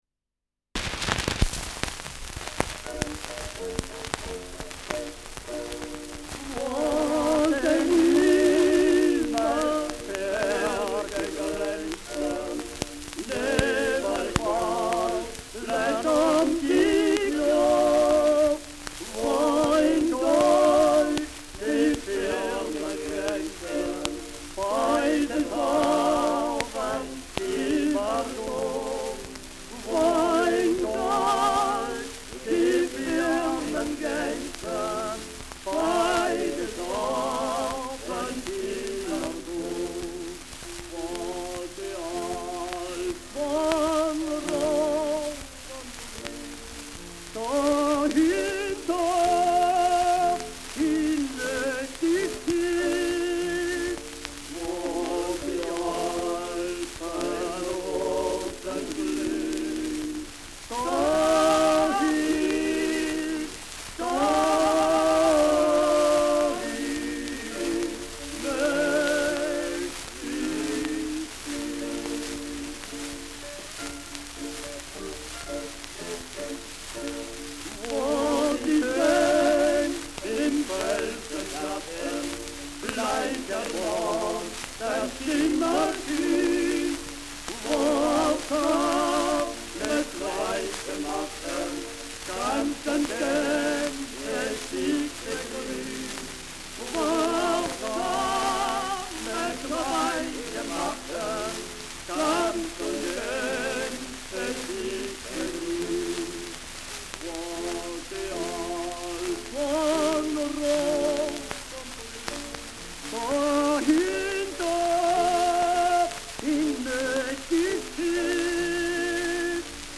Schellackplatte
Abgespielt : Stärkeres Nadelgeräusch : Gelegentlich leichtes Knacken
Nachtschwärmer-Terzett (Interpretation)
[München?] (Aufnahmeort)